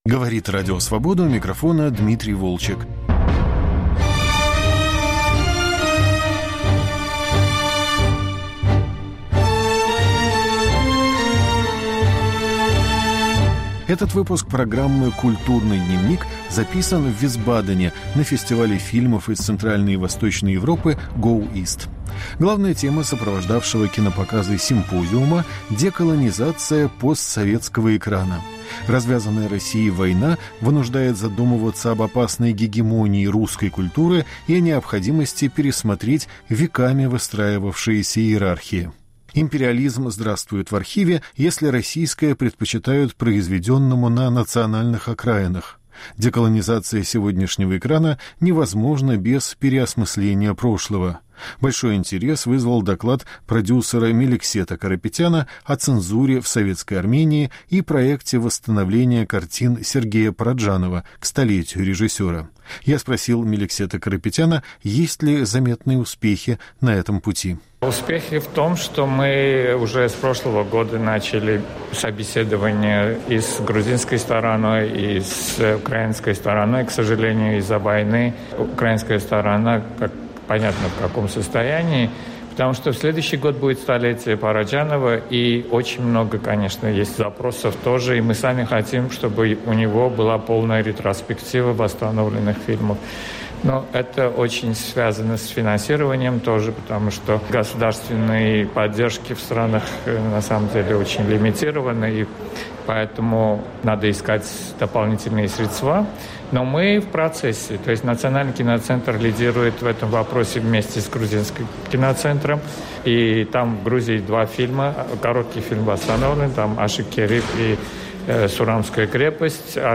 Разговоры на фестивале GoEast в Висбадене